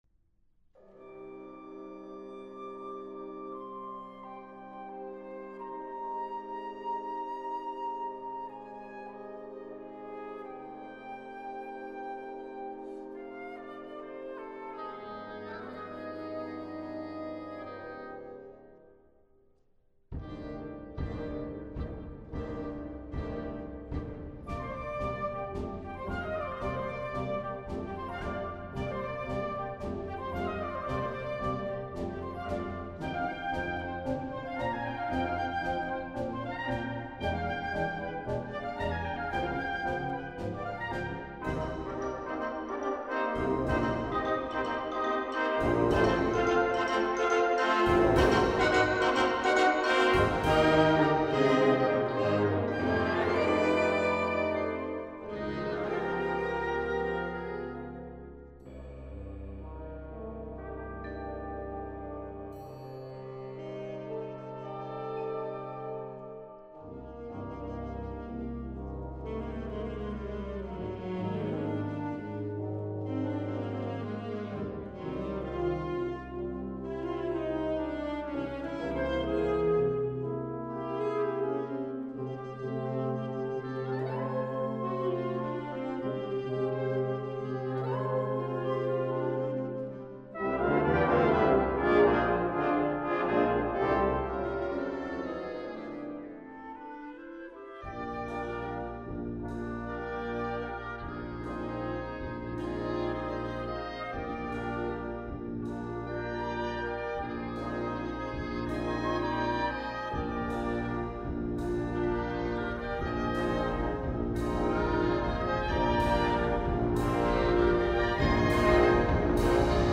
Wind Orchestra Grade 3-5